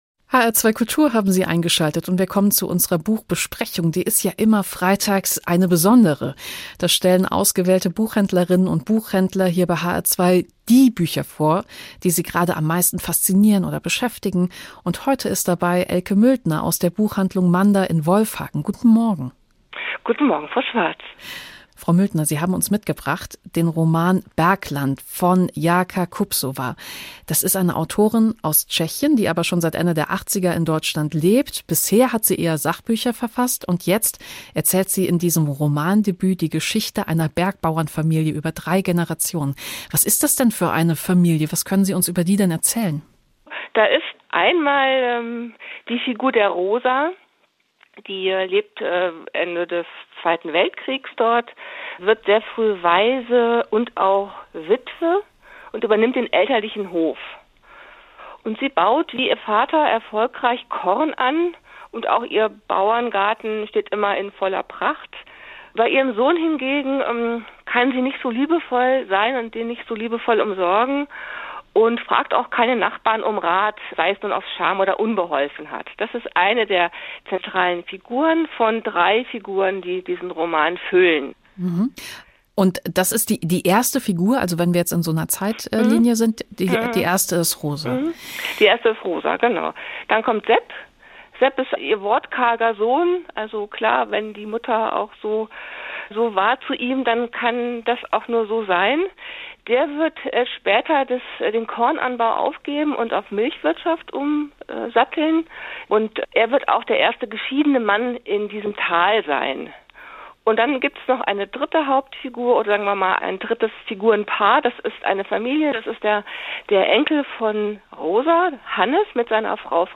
Buchvorstellung auf HR2